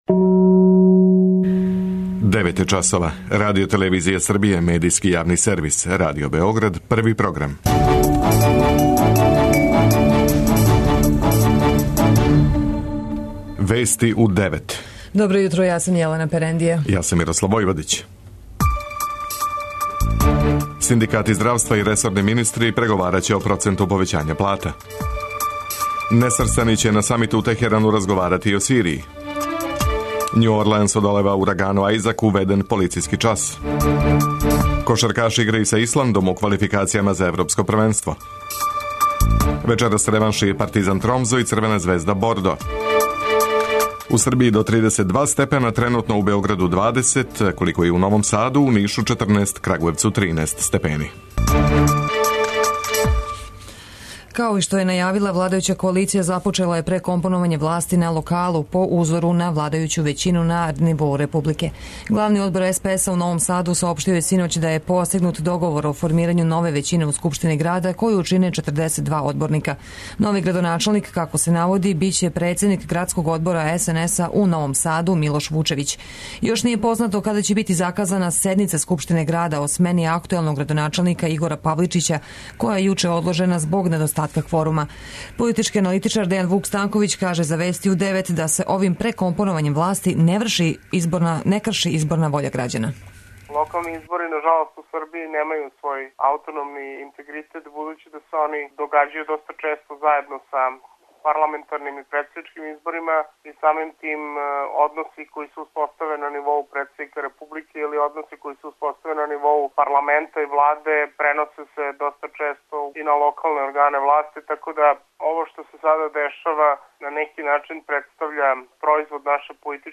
преузми : 10.04 MB Вести у 9 Autor: разни аутори Преглед најважнијиx информација из земље из света.